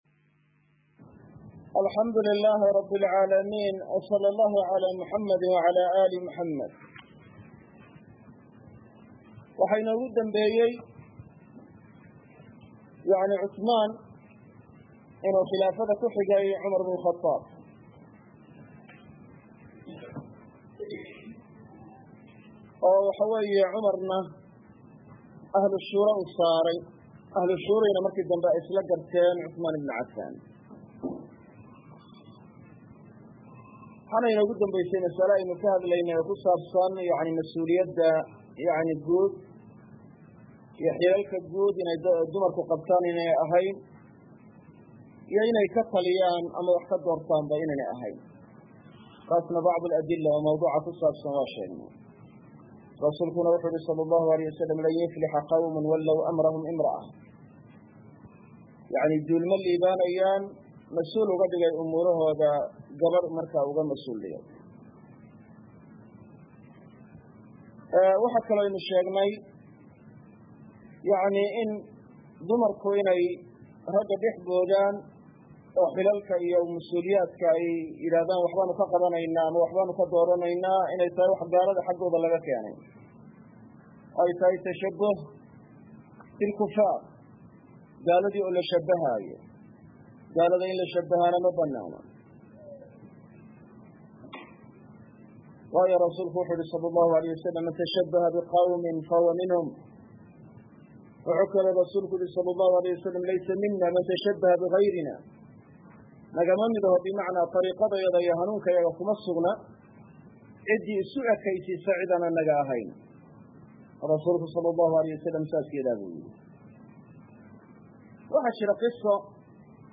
Lumcatul Ictiqaad– Darsiga 15aad - Manhaj Online |